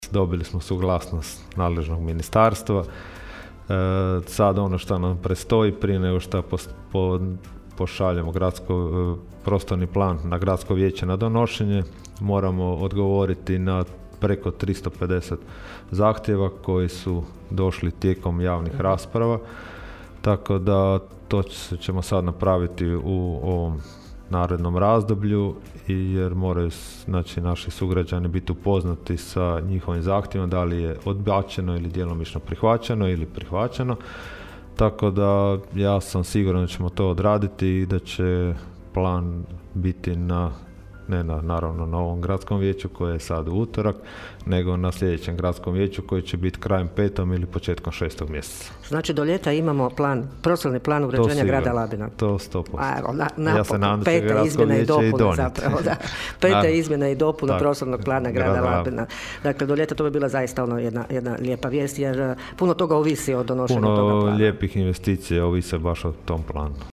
Gradonačelnik Labina Donald Blašković u subotnjim je Gradskim minutama najavio skorašnje donošenje Petih Izmjena i dopuna Prostornog plana uređenja Grada Labina: (
ton – Donald Blašković), rekao je gradonačelnik Blašković.